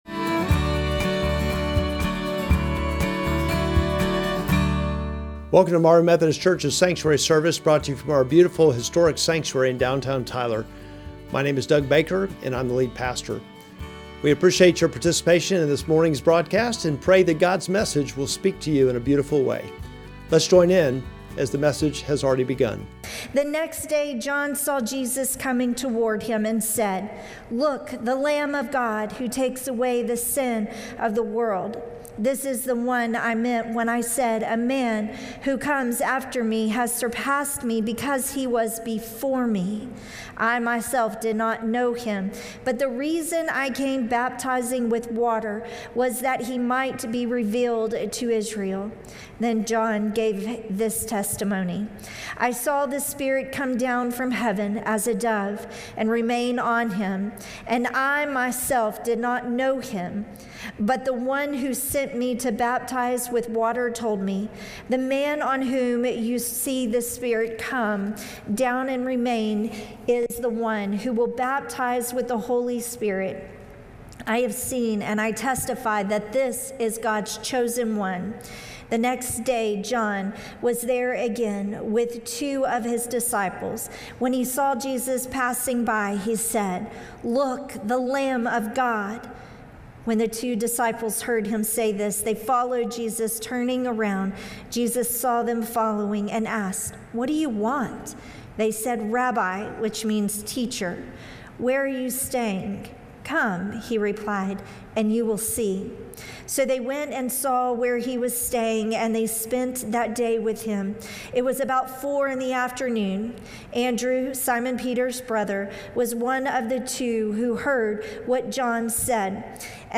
Sermon text: John 1:29-42